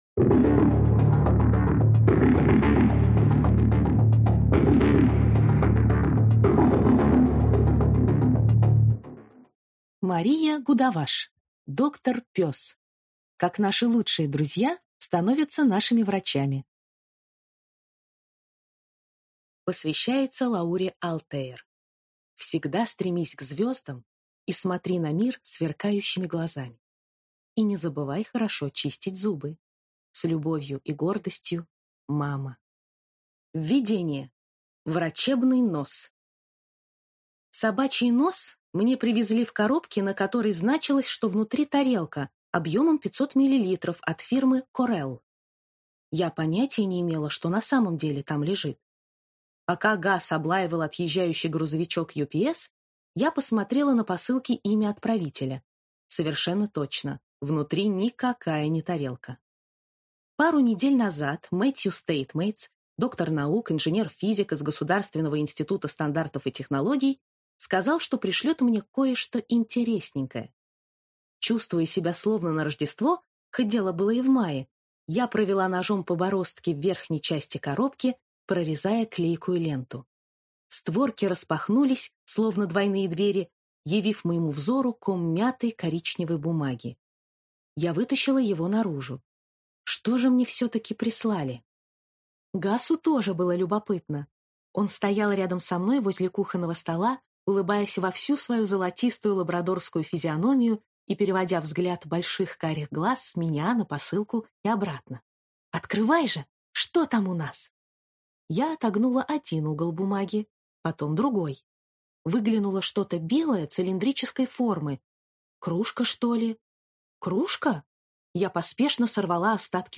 Аудиокнига Доктор Пес. Как наши лучшие друзья становятся нашими врачами | Библиотека аудиокниг